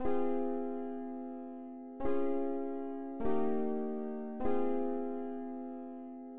掩饰钢琴
Tag: 150 bpm Trap Loops Piano Loops 1.08 MB wav Key : Unknown